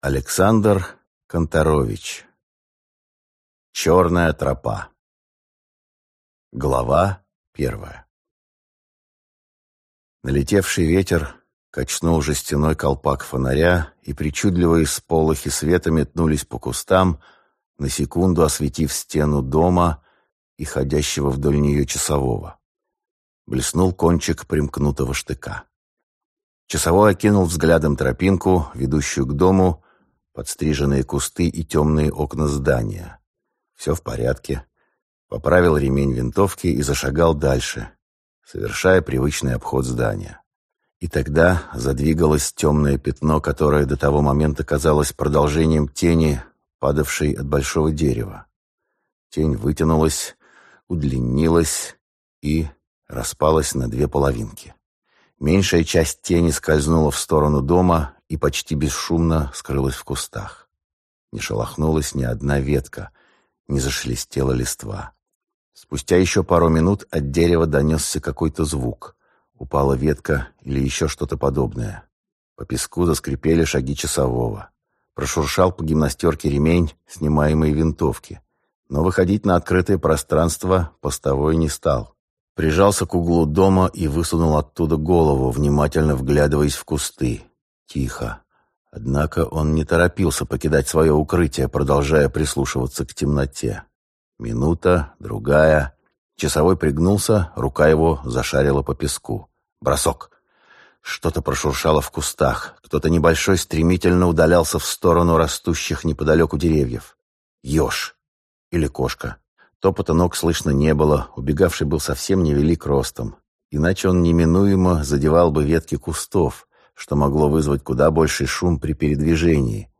Аудиокнига Черные тропы - купить, скачать и слушать онлайн | КнигоПоиск